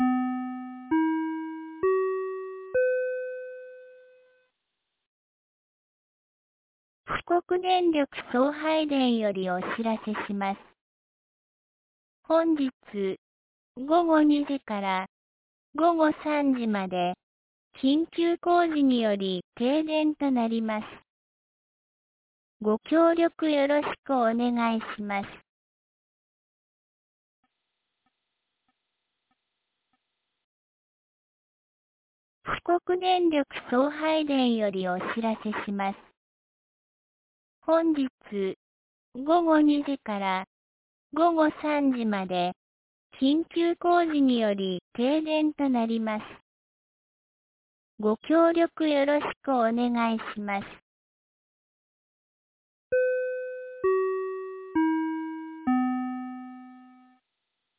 2024年05月24日 12時45分に、安芸市より畑山へ放送がありました。